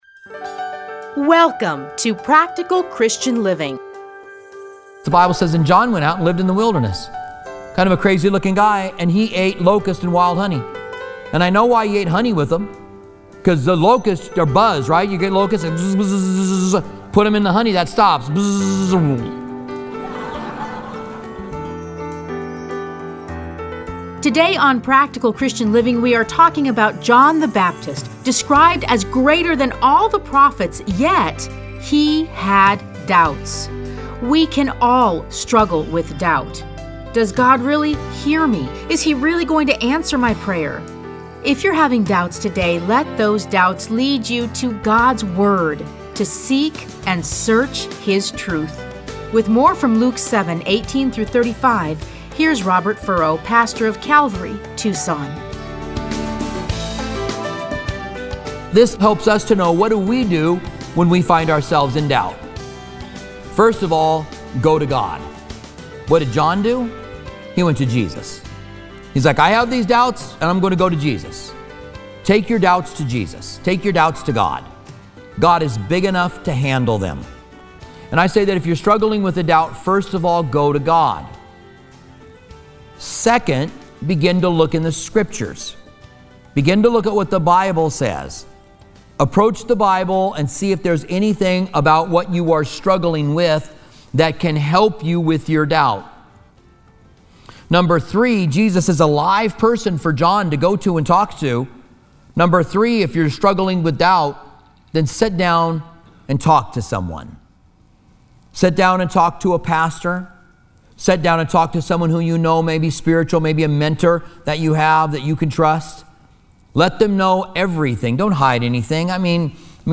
Listen to a teaching from Luke 7:18-35.